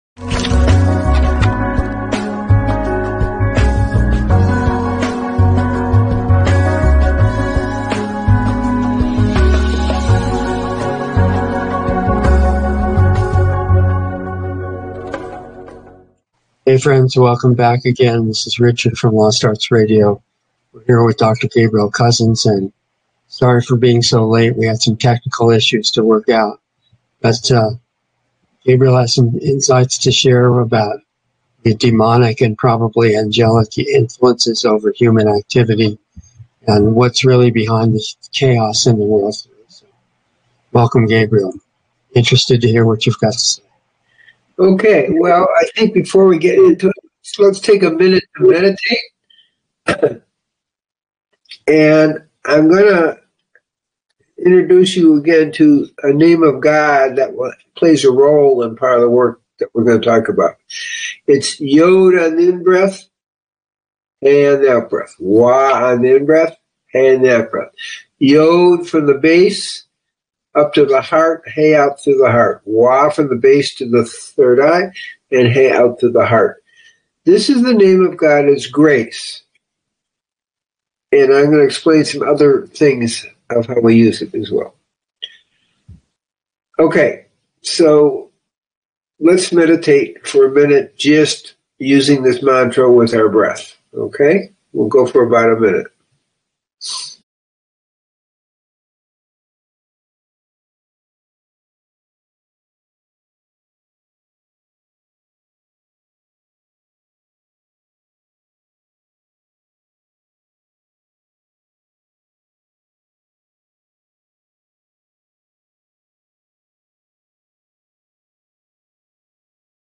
Dialogs